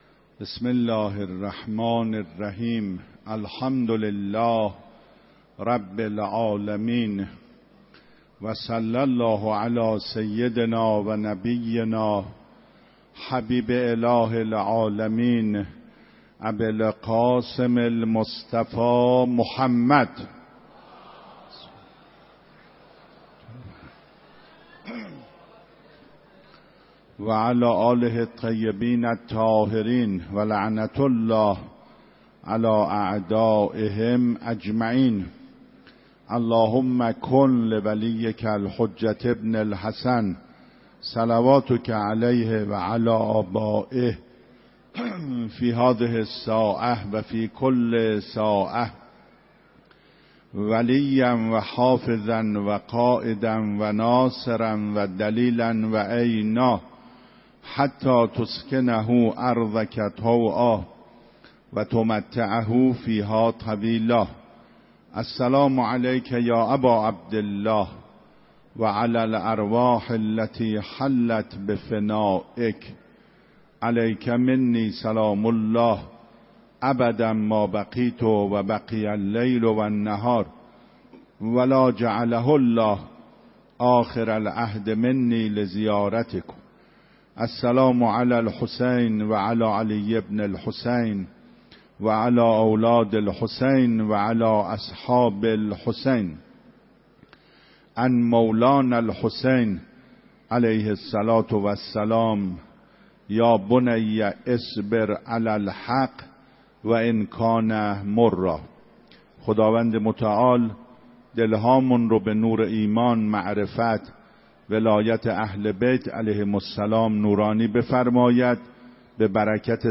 12 مهر 96 - حرم حضرت معصومه - صبر در بلاها و گرفتاری ها